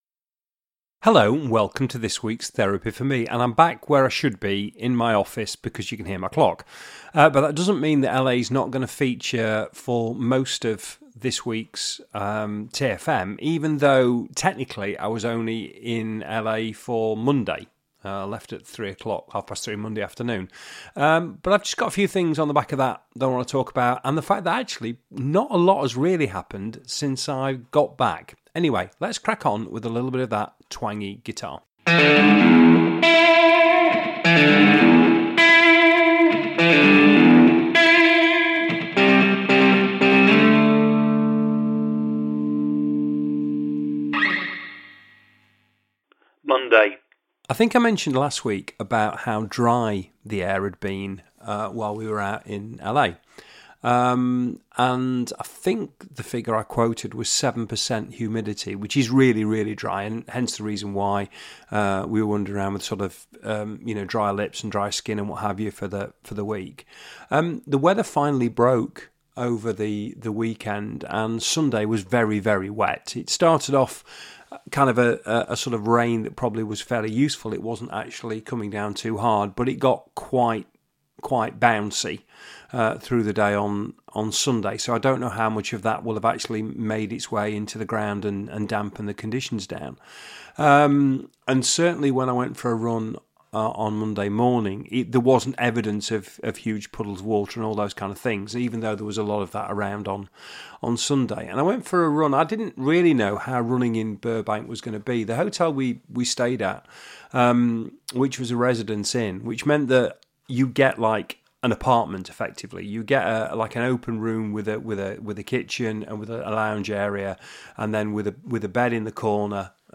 It's remains loose in style, fluid in terms of content and raw - it's a one take, press record and see what happens, affair.